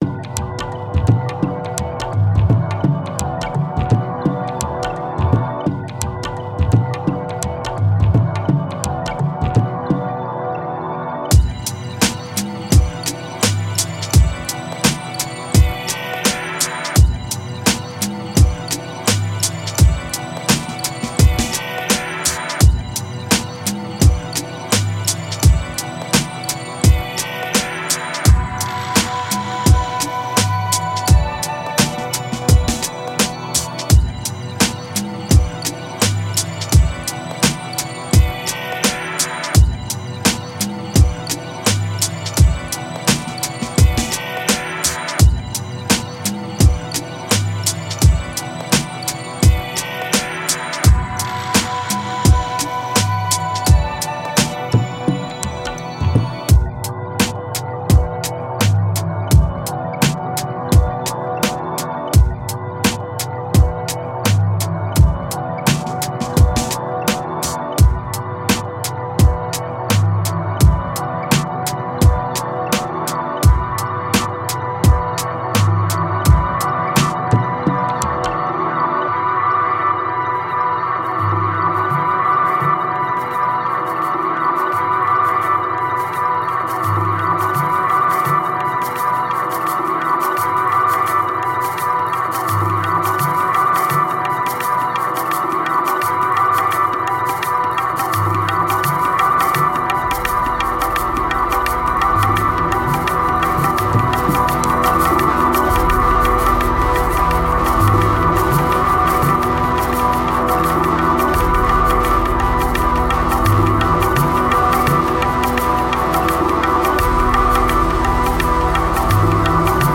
Le tout est un mélange de hip hop et d'ambient